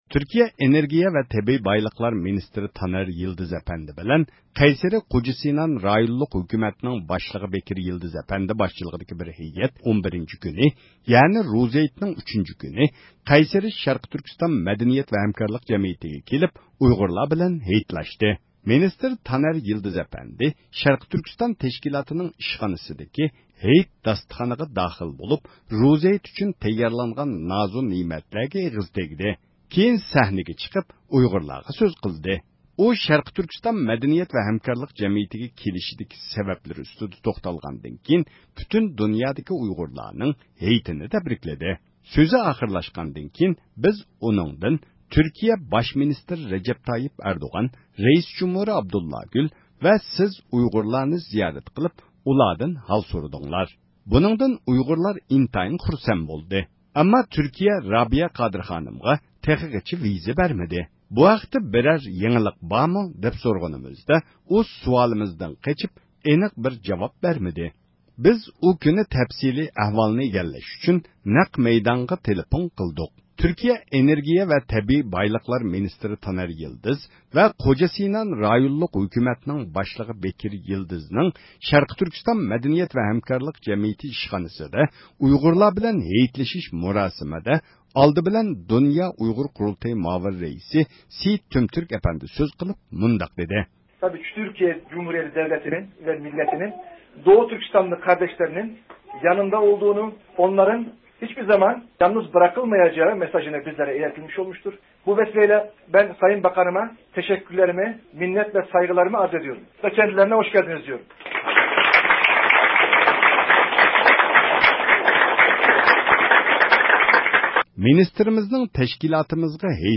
بىز ئۇ كۈنى تەپسىلى ئەھۋالنى ئىگىلەش ئۈچۈن نەق مەيدانغا تېلېفون قىلدۇق.